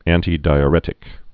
(ăntē-dīə-rĕtĭk, ăntī-)